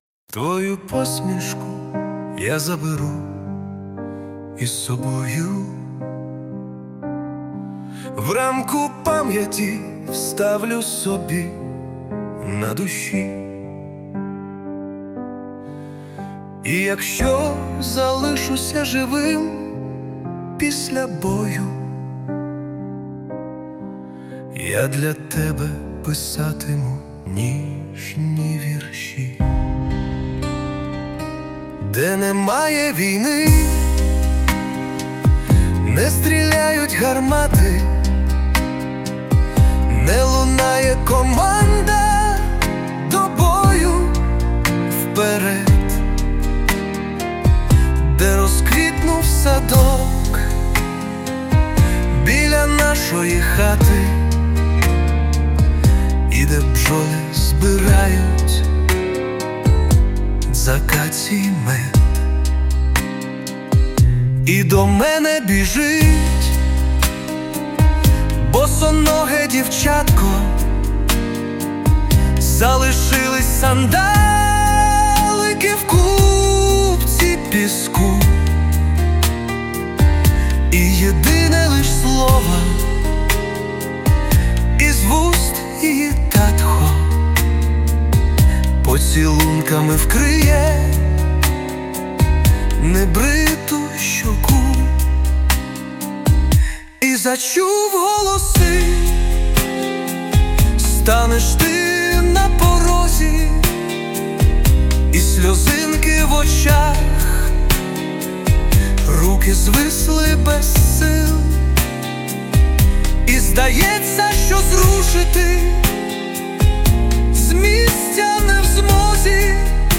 ТИП: Пісня
СТИЛЬОВІ ЖАНРИ: Ліричний
Вдало на зворушливі слова підібрана мелодія.
Справді вийшло ніжно. 17 hi